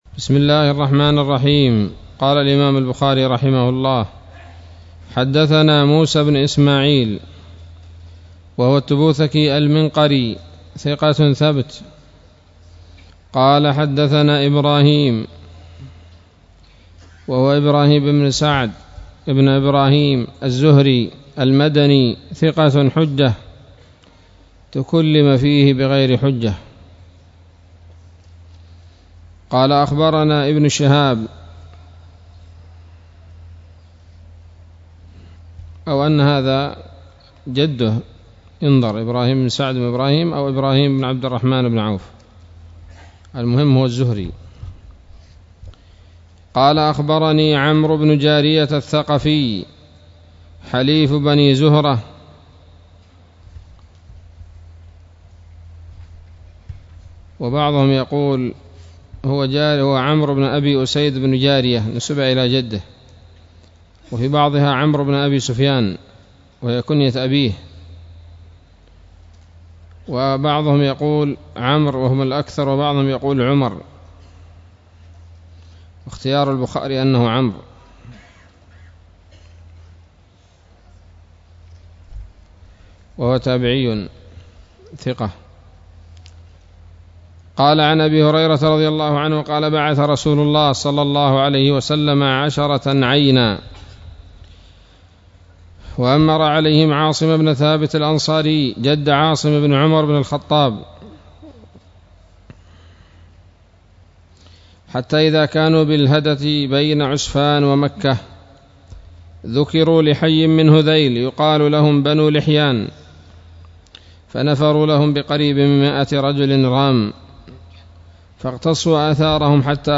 الدرس السادس عشر من كتاب المغازي من صحيح الإمام البخاري